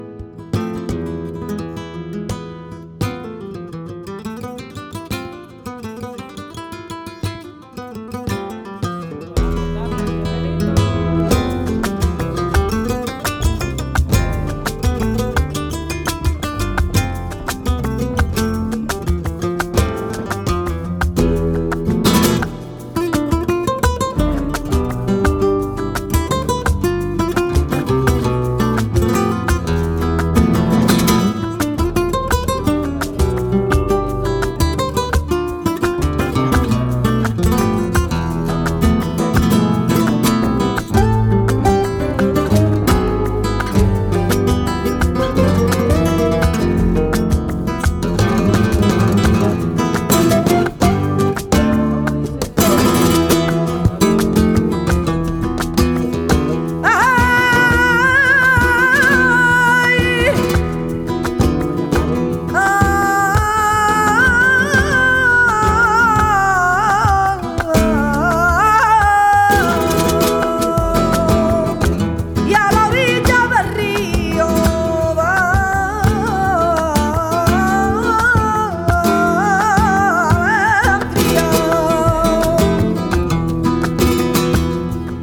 Tangos 3:31